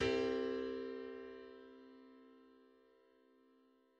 Harmonic seventh chord
The harmonic seventh chord on C
The harmonic seventh chord is a dominant seventh chord formed by a major triad plus a harmonic seventh interval.